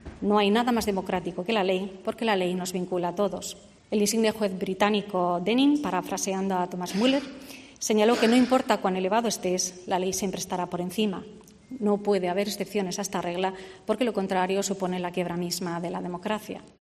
En la apertura del año judicial, María José Segarra ha afirmado que "las legítimas aspiraciones de una parte de la sociedad" catalana "deben ser encauzadas a través del ordenamiento jurídico".